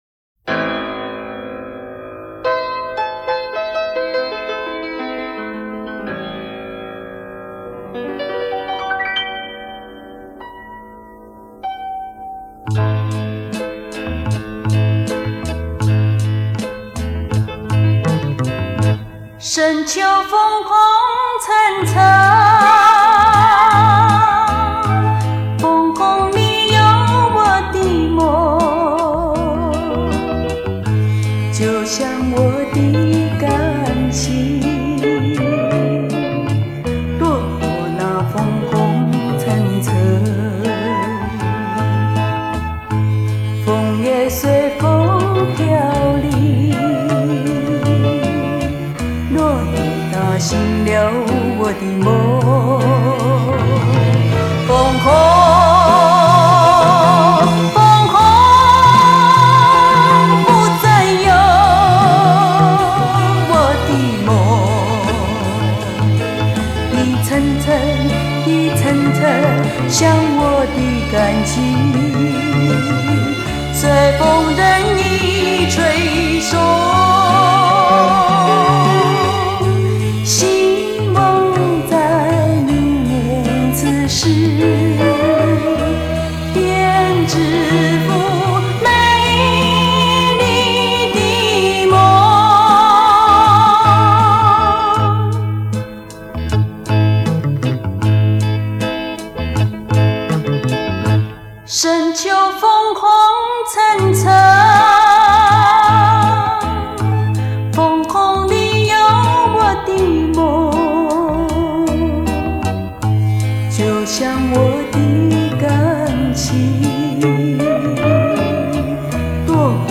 [流行音乐]